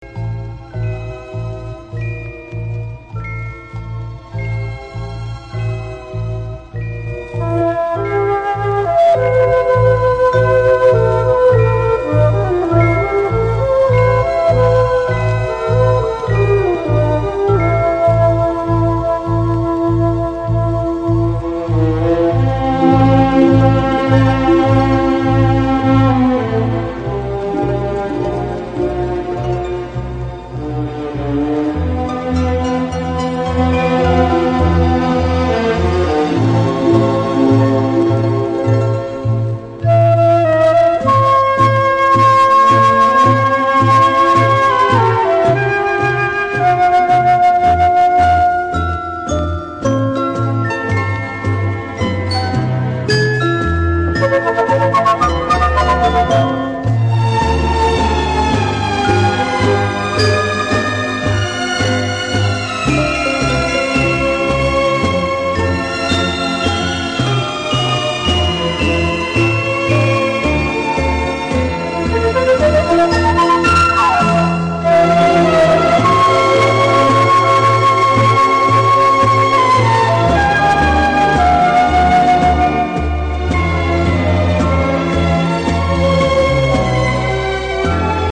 Есть кусок инструм записи отвратительного качества.Но мелодия слышится чётко.
Интересная обработка.
Очень нежная аранжировка.